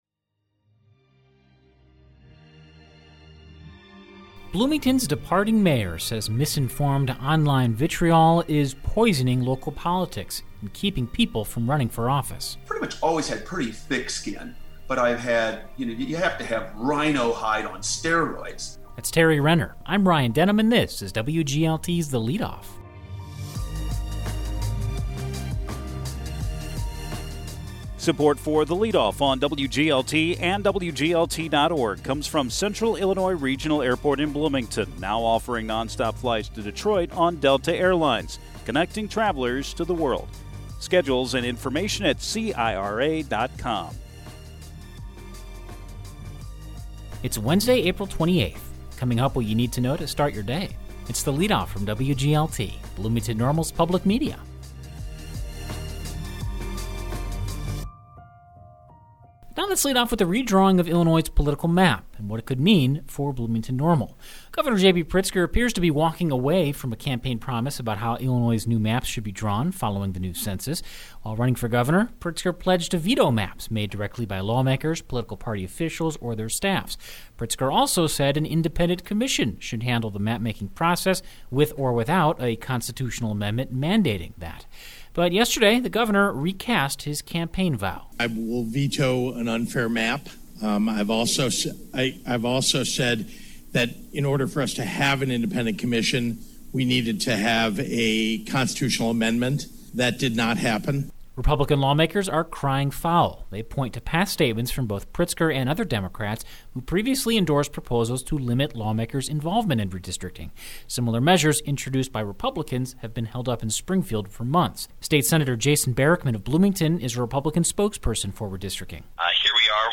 You'll hear about a shift in the redistricting process in Illinois, plus an exit interview with Mayor Tari Renner.